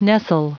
Prononciation du mot nestle en anglais (fichier audio)
Prononciation du mot : nestle